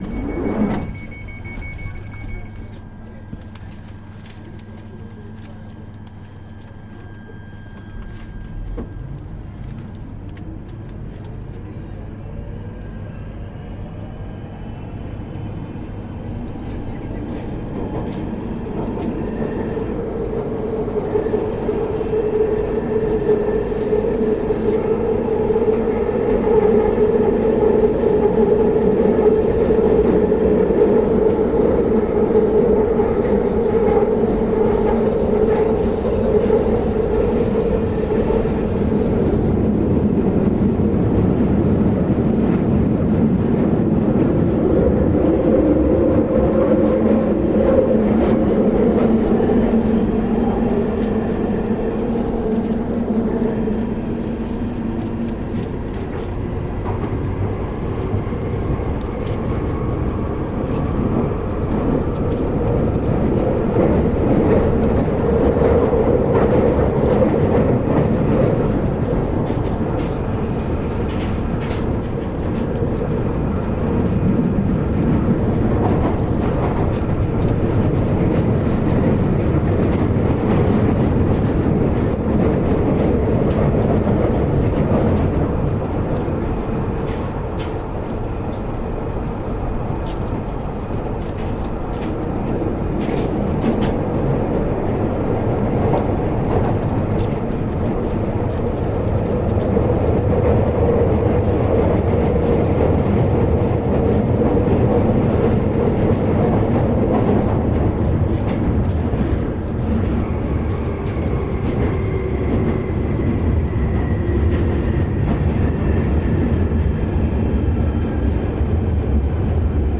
Field recording from the London Underground by Cities and Memory.